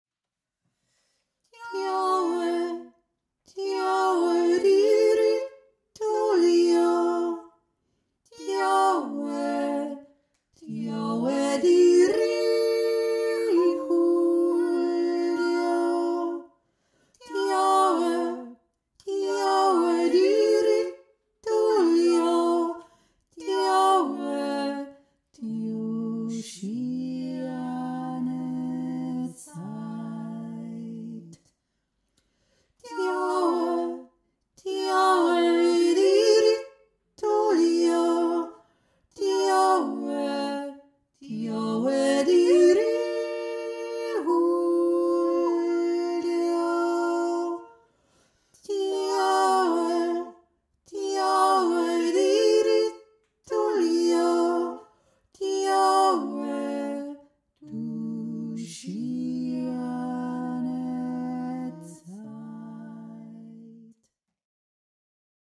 2. Stimme
aiblinger-weise-mittlere-stimme.mp3